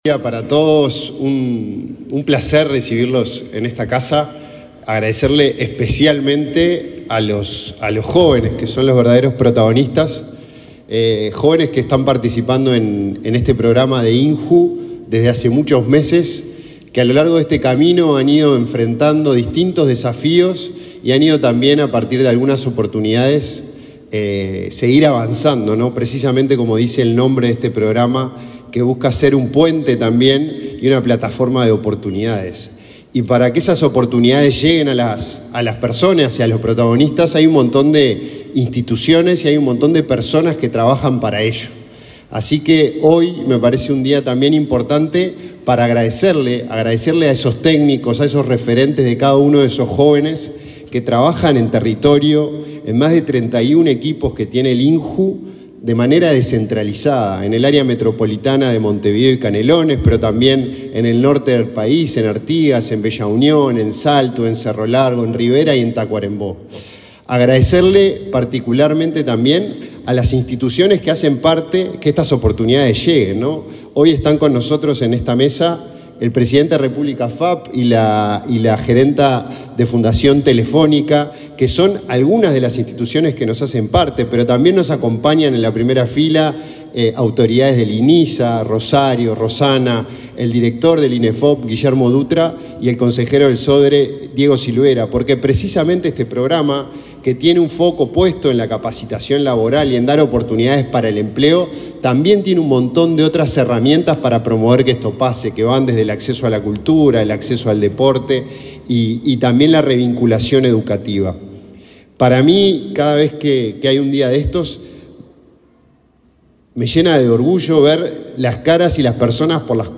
Palabra de autoridades en el INJU